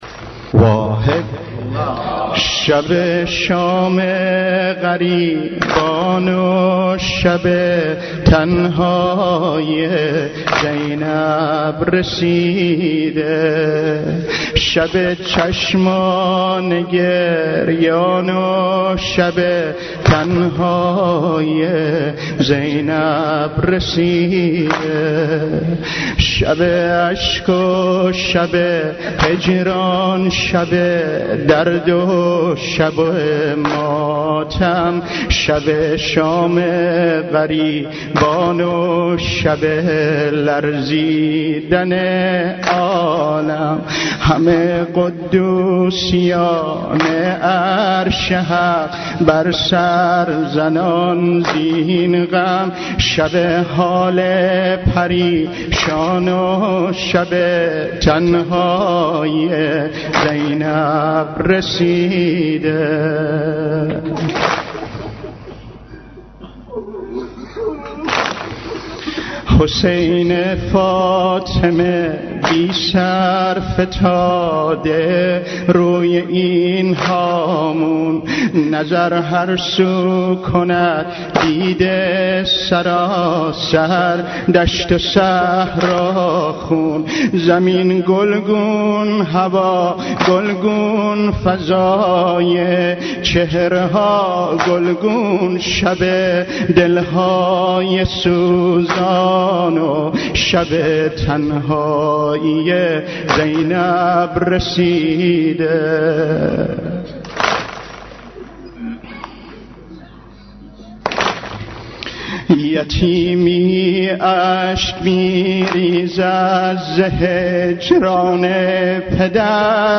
» و نوحه موسیقایی این اثر را به مخاطبان ایکنا تقدیم کرد.
موسیقی عاشورایی